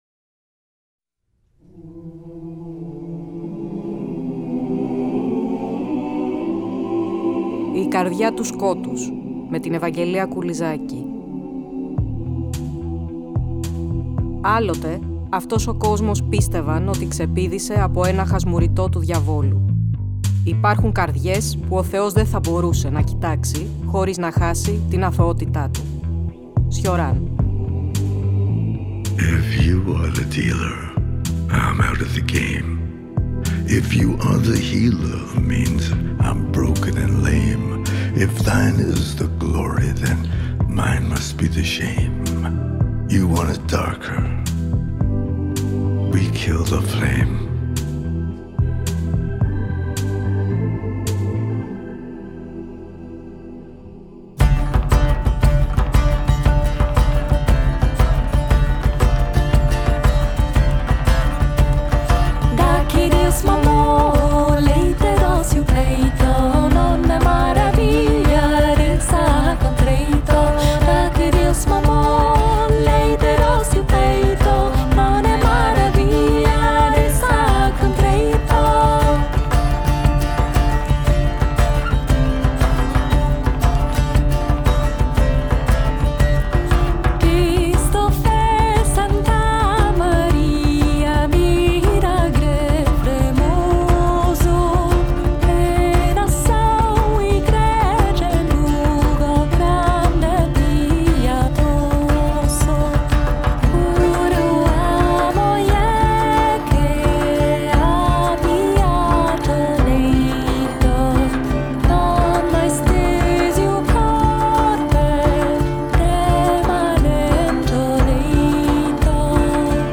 Ακούστε το 9ο επεισόδιο του νέου Κύκλου της εκπομπής, που μεταδόθηκε την Κυριακή 02 Νοεμβρίου από το Τρίτο Πρόγραμμα.